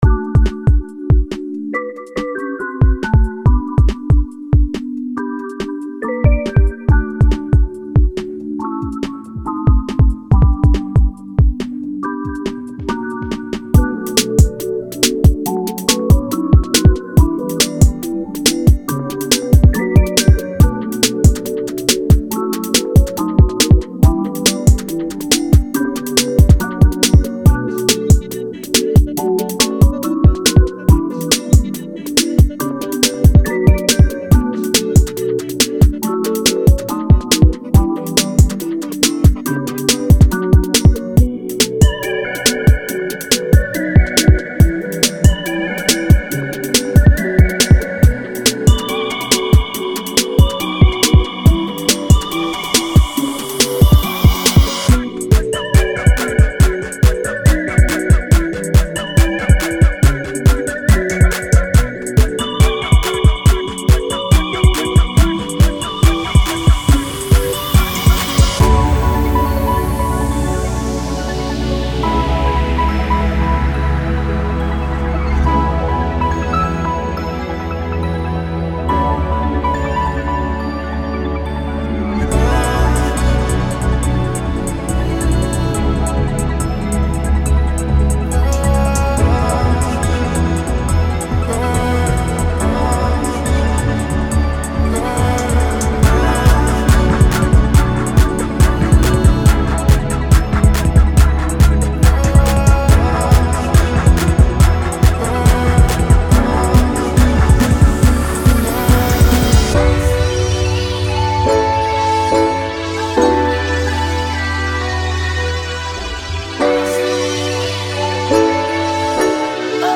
With big riffs, catchy melodies, punchy drums, and vocals